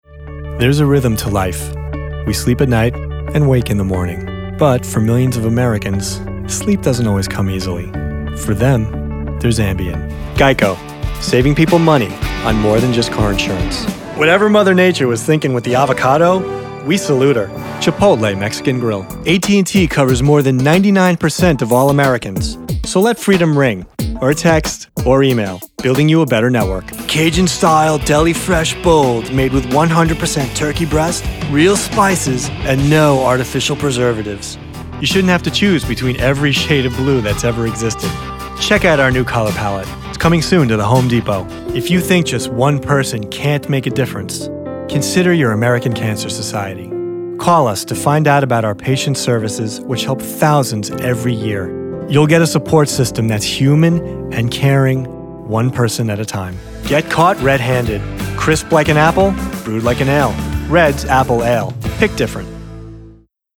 Commercial Demo
English (North American)